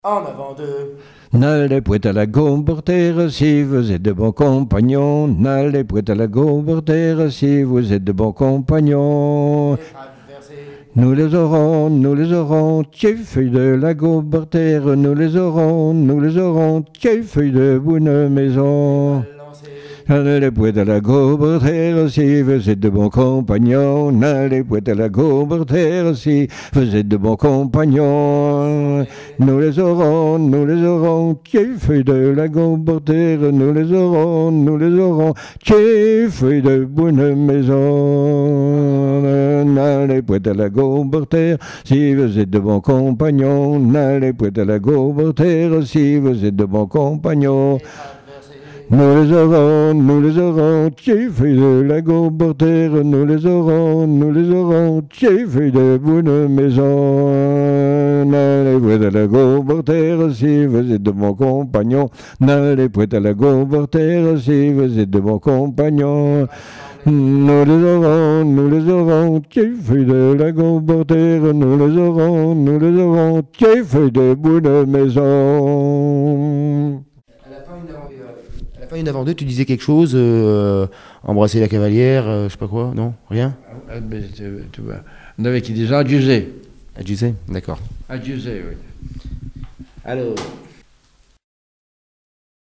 Divertissements d'adultes - Couplets à danser
branle : avant-deux
Pièce musicale inédite